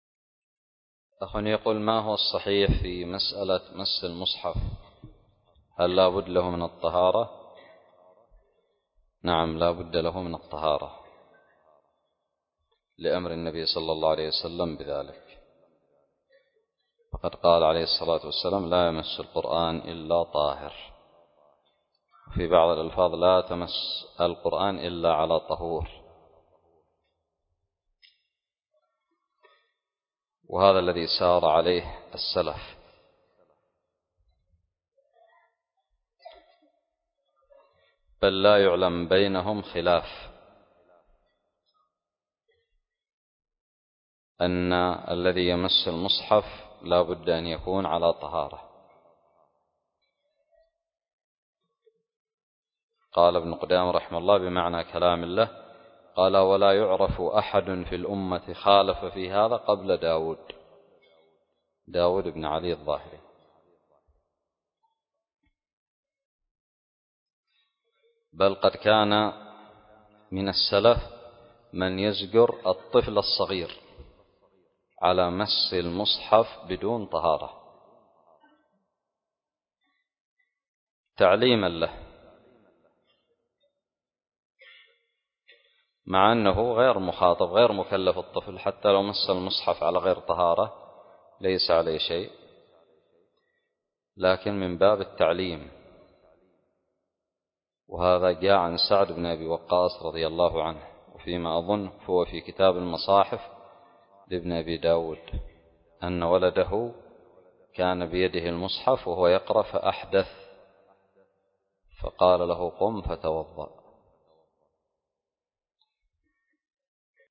سؤال قدم لفضيلة الشيخ حفظه الله